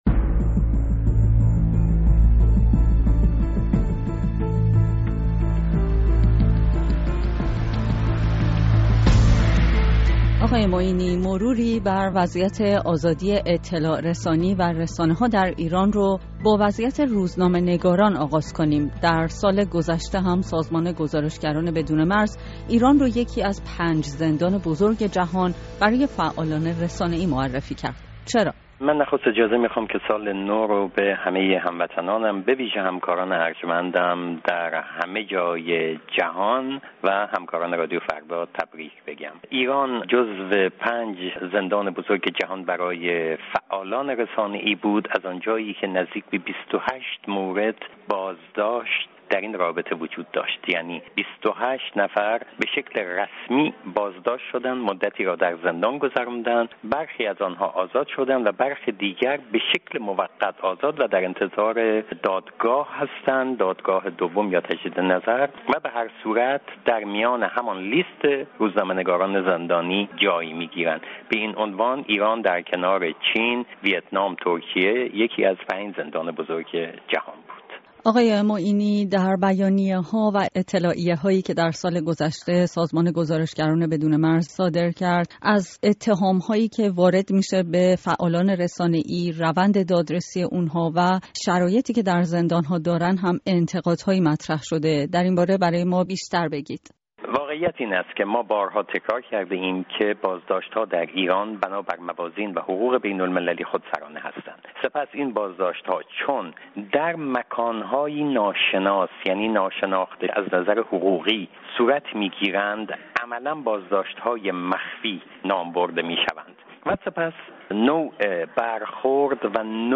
مروری بر وضعیت روزنامه‌نگاران، رسانه‌ها و آزادی بیان در ایران در گفت‌وگو